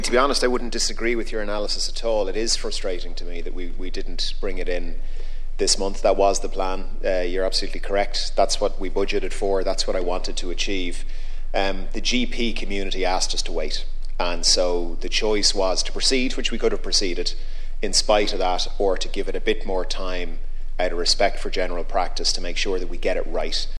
Being questioned in the Dáil, Health Minister Stephen Donnelly didn’t say when the 500,000 extra patients will get their cards: